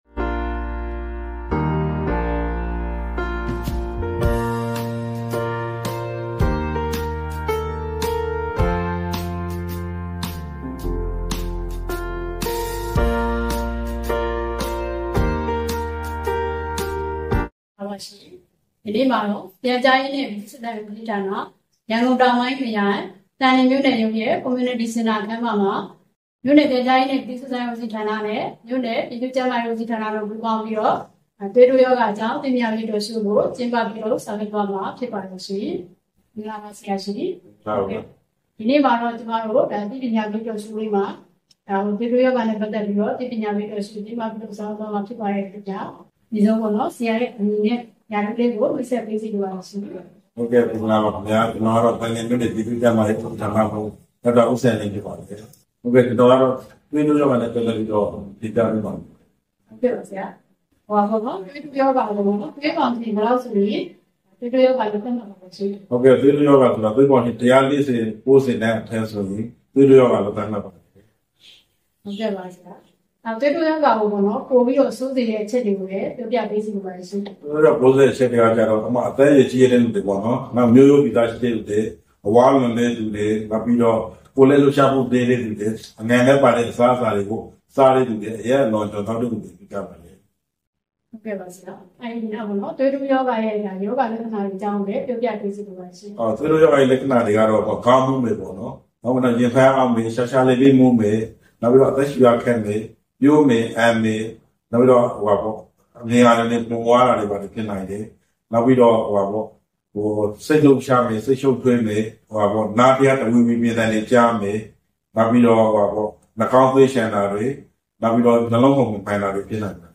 သန်လျင်မြို့၌သွေးတိုးရောဂါအကြောင်း Talk Show